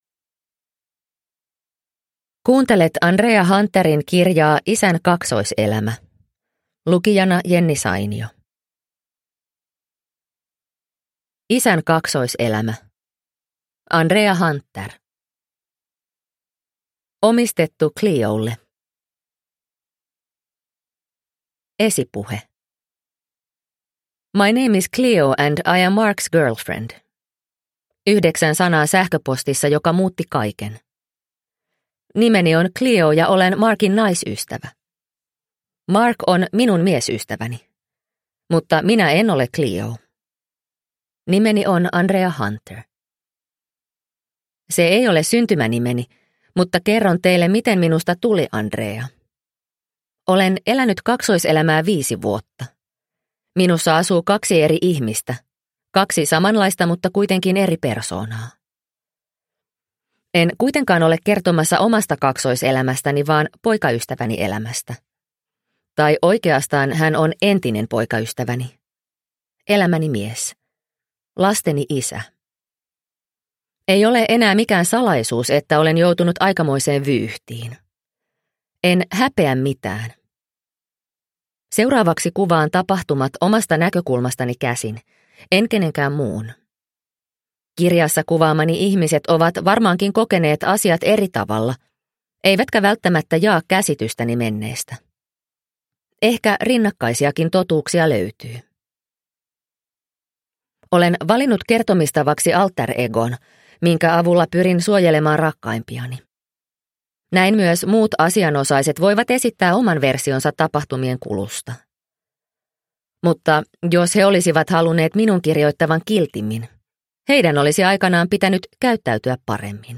Isän kaksoiselämä – Ljudbok – Laddas ner